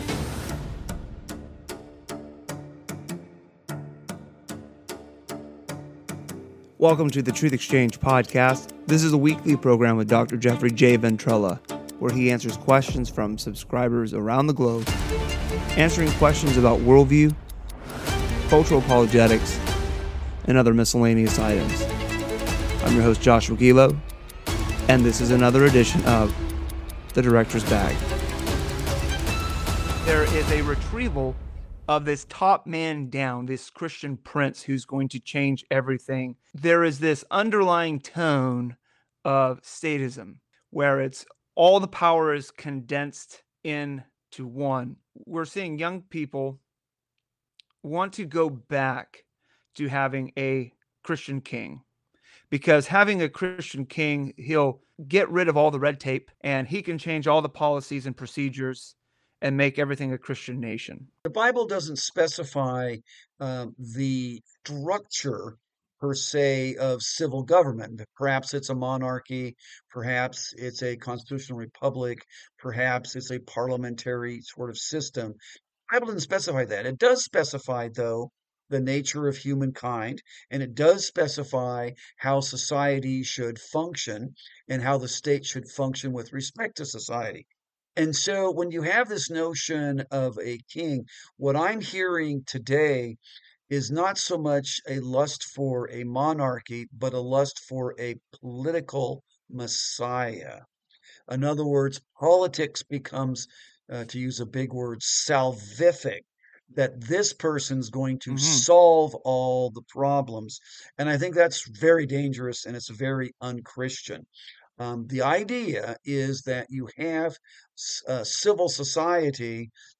This episode of the Director's Bag is a continuation of a discussion